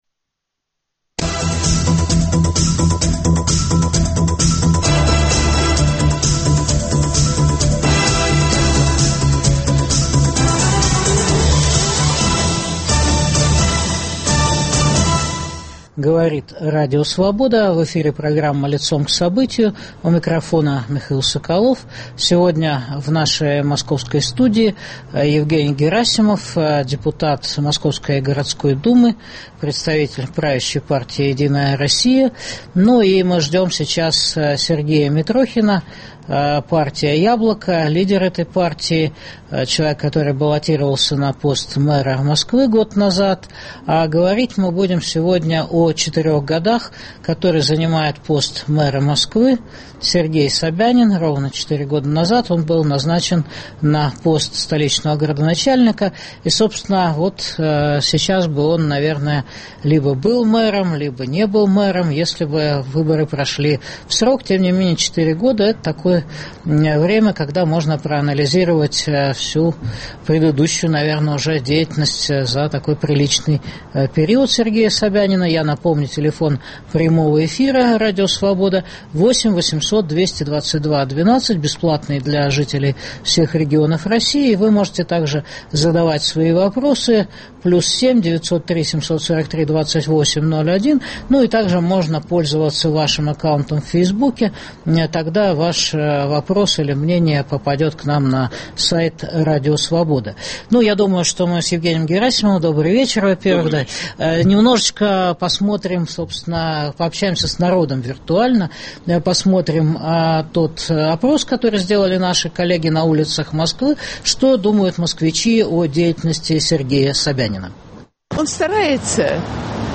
Что сделано за 4 мэрских года Сергея Собянина после Юрия Лужкова? Спорят политики Евгений Герасимов («Единая Россия») и Сергей Митрохин («Яблоко»).